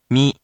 In romaji, 「み」 is transliterated as 「mi」which sounds sort of like the mee in「meet」or simply close to the American or posh pronunciation of the word 「me」